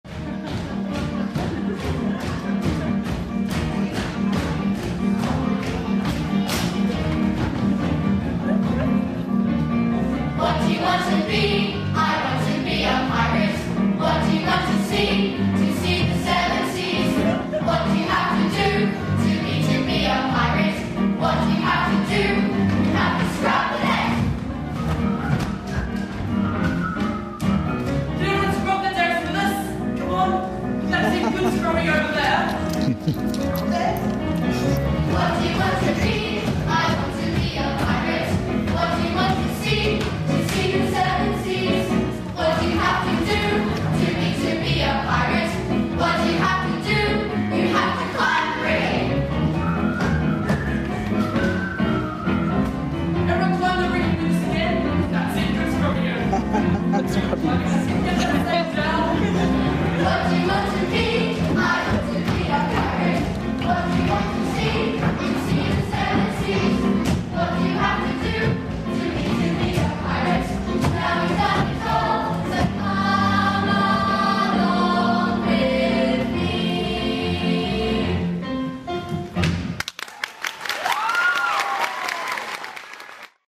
The opening song from Imogen & The Pirates. 12th January performance Marion Street Theatre for Young People.